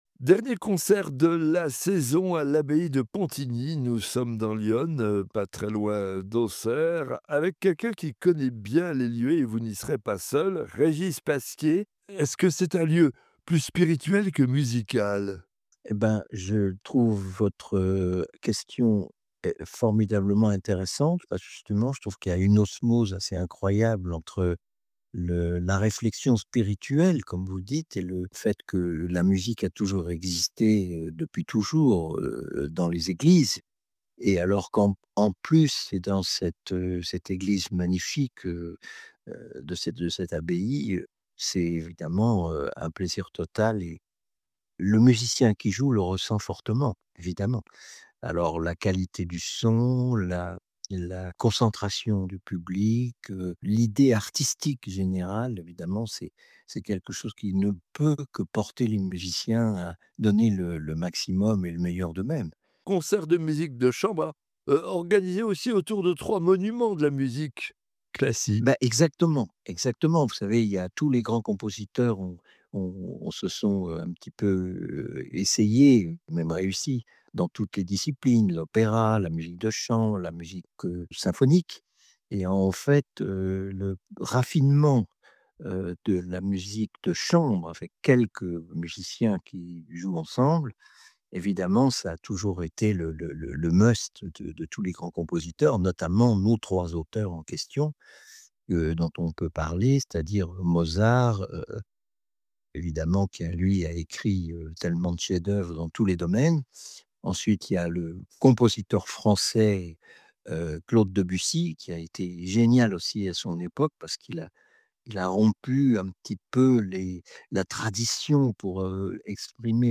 Rencontre avec Régis Pasquier, lorsque l’histoire d’une grande famille musicienne se perpétue…